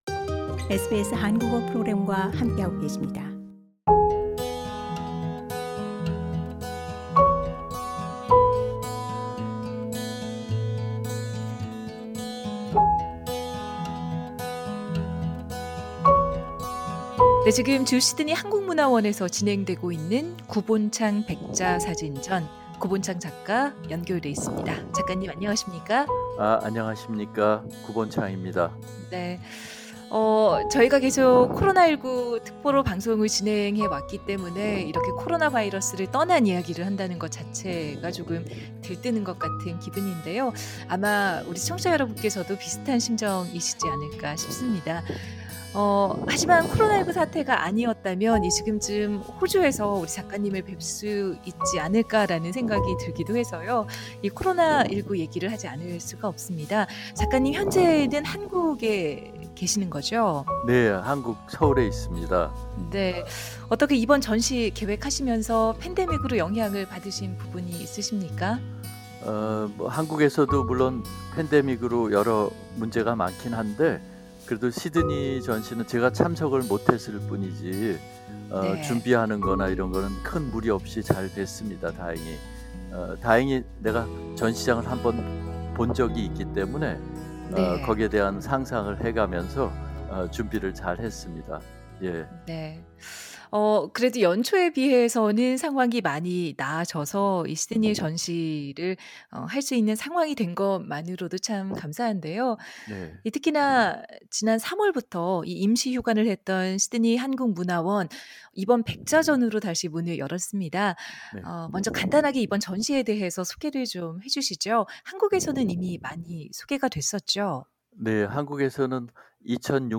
SBS Korean program talks to Koo Bohnchang about the exhibition.